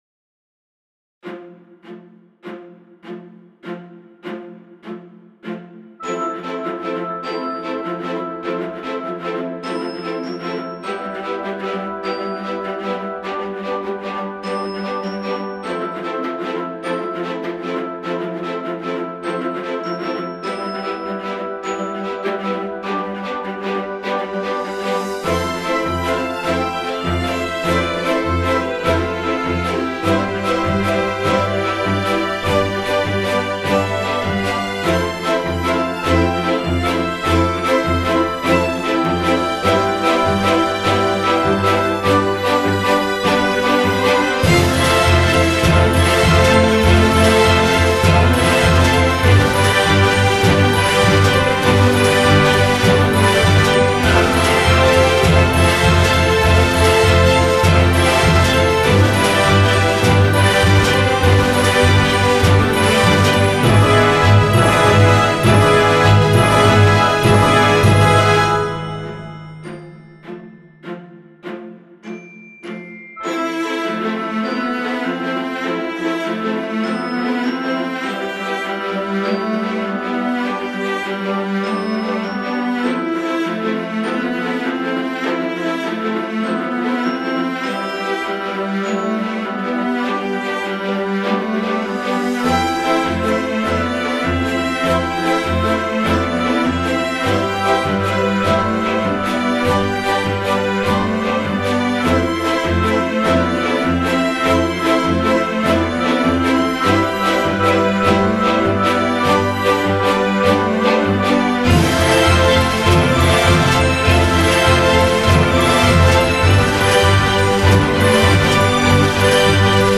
HEARTFELT